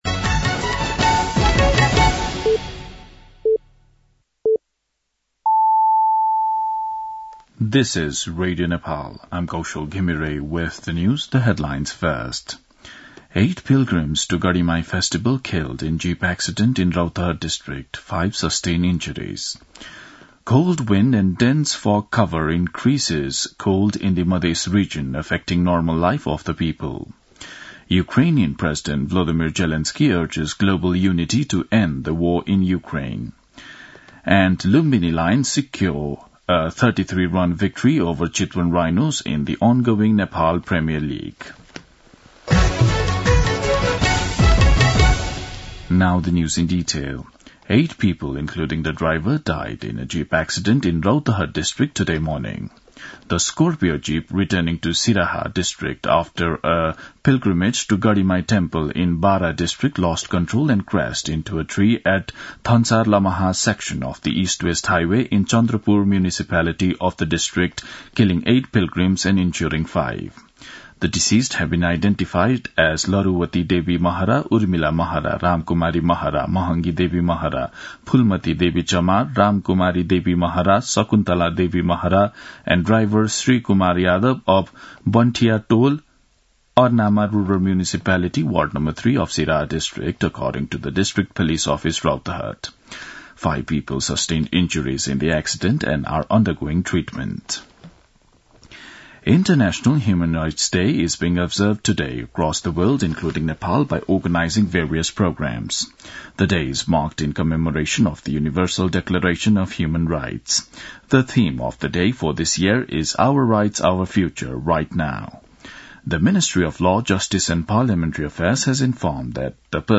दिउँसो २ बजेको अङ्ग्रेजी समाचार : २६ मंसिर , २०८१
2-pm-English-news-.mp3